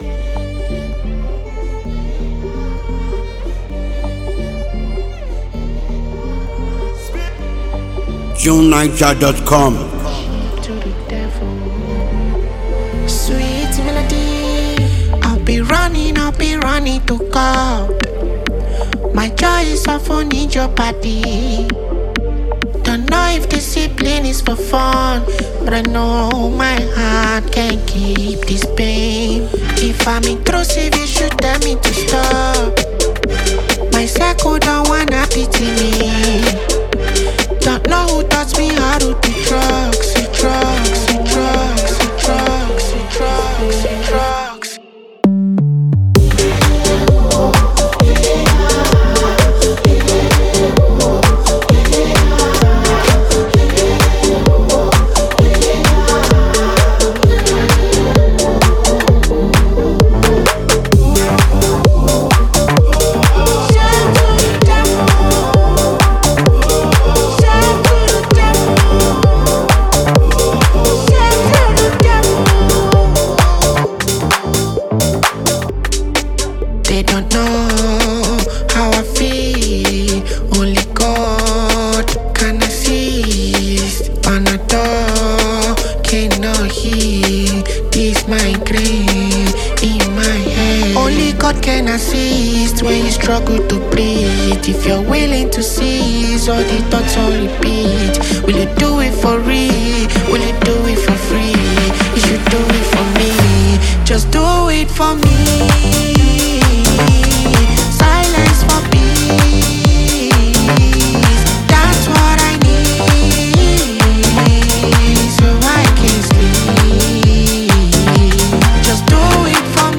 comfortable and soothing